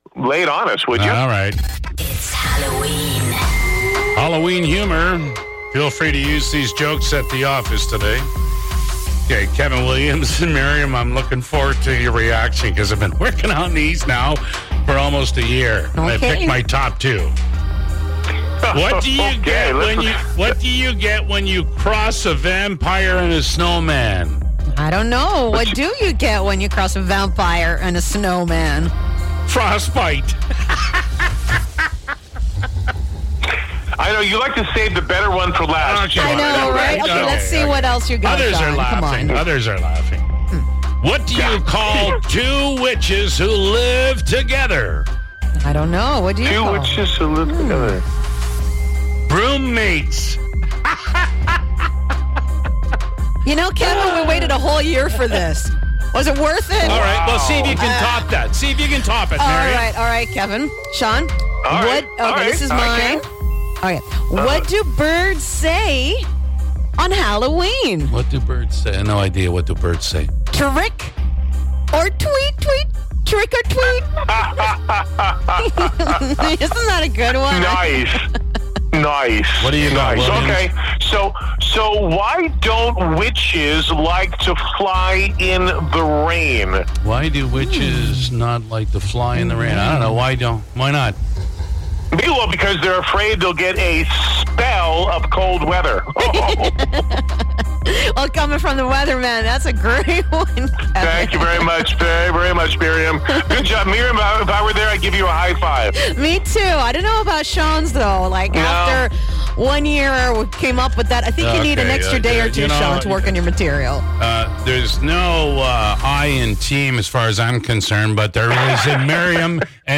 crack each other up with these spoooky jokes